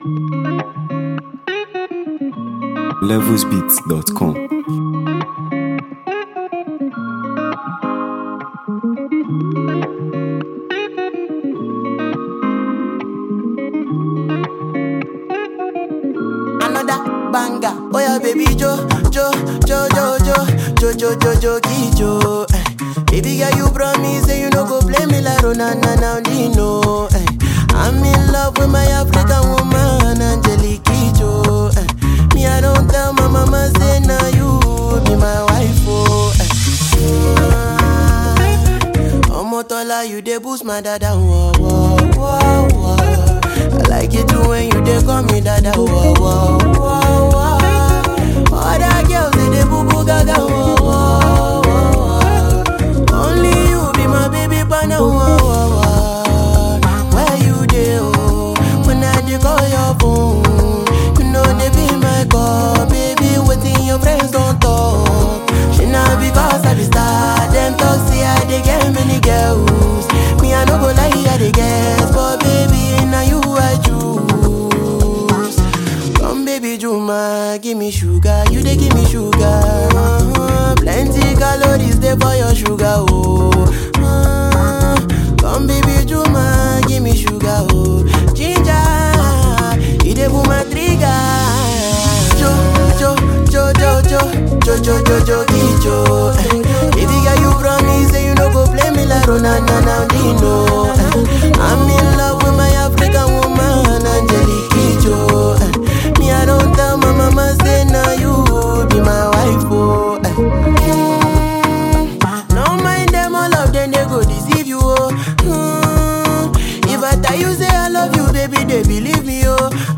if you enjoy upbeat, melodious Afrobeats with replay value